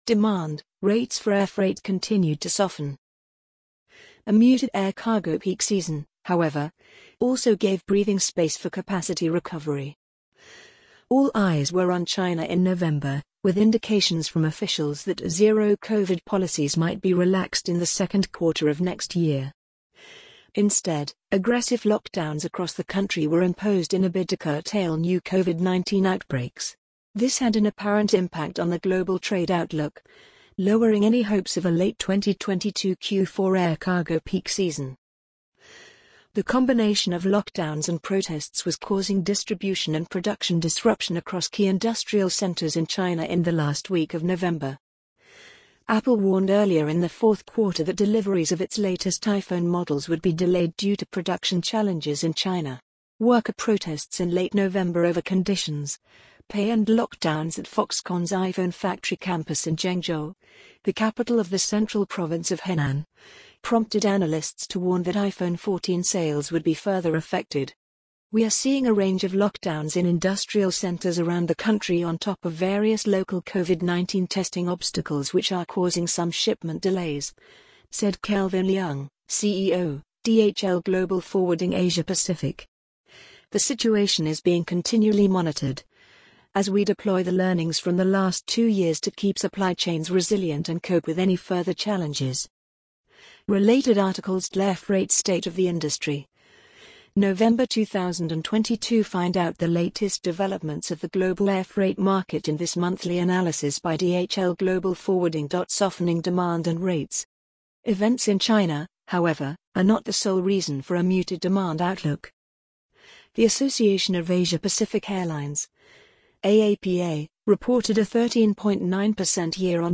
amazon_polly_33508.mp3